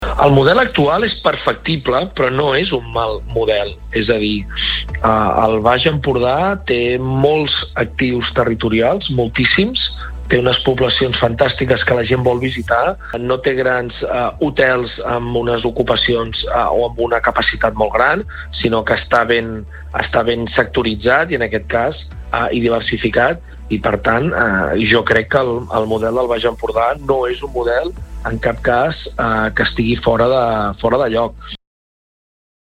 Entrevistes SupermatíNotícies